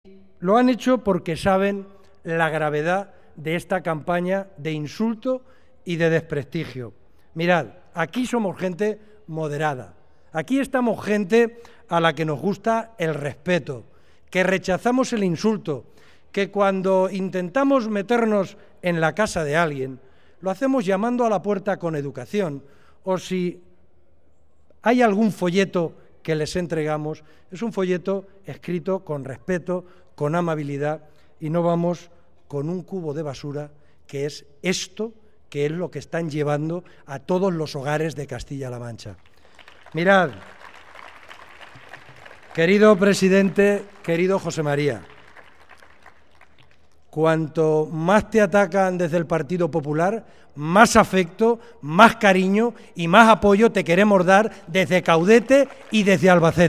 El cabeza de lista por la provincia de Albacete, Francisco Pardo, que precedió a José María Barreda en el uso de la palabra, aseguró que desde Caudete y Albacete se trata de transmitir fuerza e ilusión «para llevarte a meter el gol de la victoria del 22 de mayo».